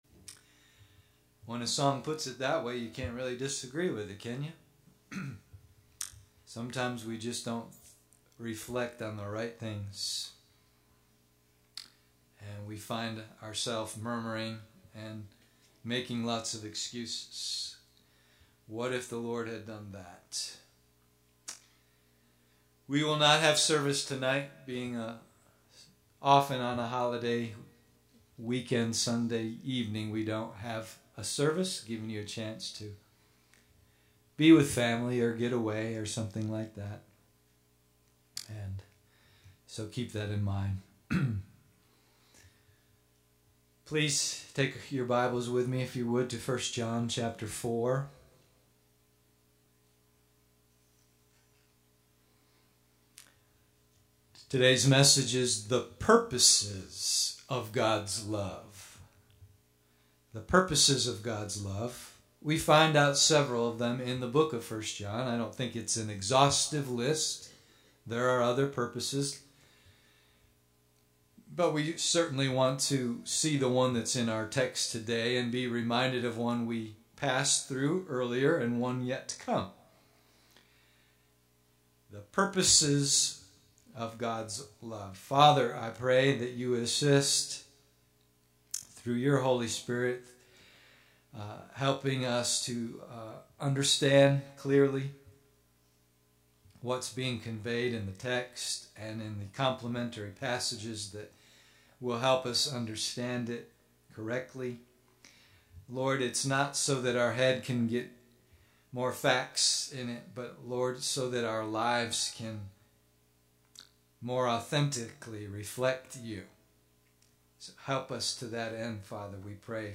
The Purposes of God’s Love Sunday AM